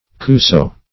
Search Result for " kousso" : The Collaborative International Dictionary of English v.0.48: Kousso \Kous"so\, n. (Bot.) An Abyssinian rosaceous tree ( Brayera anthelmintica ), the flowers of which are used as a vermifuge.